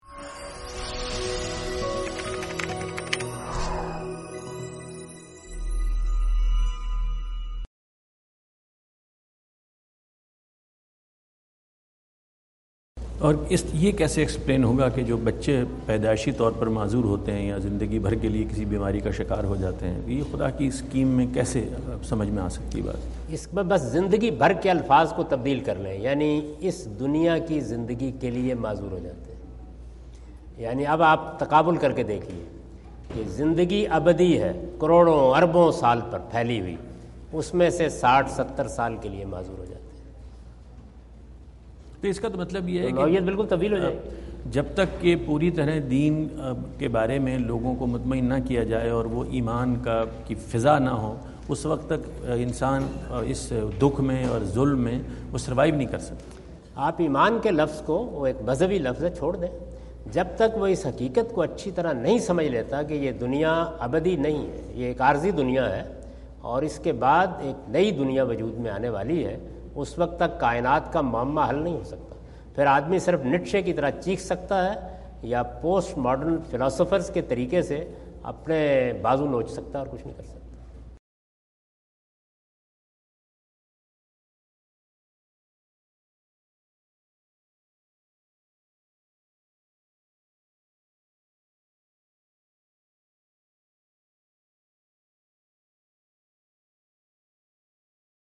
Javed Ahmad Ghamidi answer the question about "Born Ailments and Grand Scheme of God" During his US visit in Dallas on September 17, 2017.